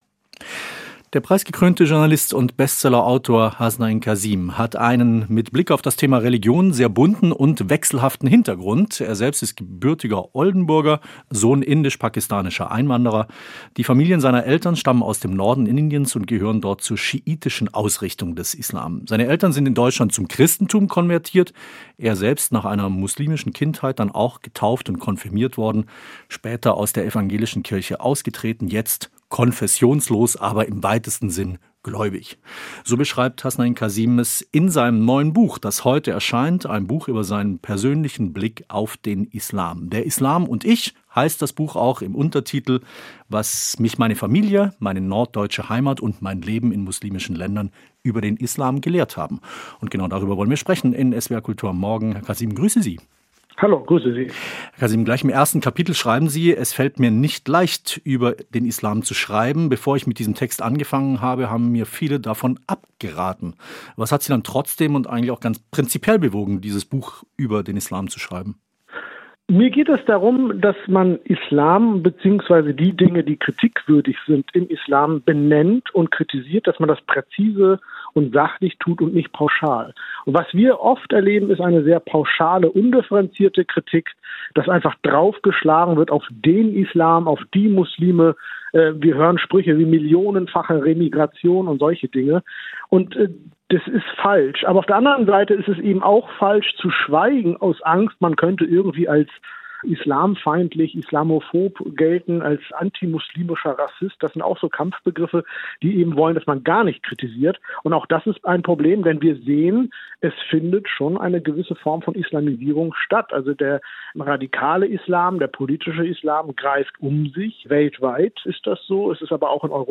Oft erlebten wir heute eine „sehr pauschale, undifferenzierte Kritik“, so der Journalist und Autor im Gespräch mit SWR Kultur.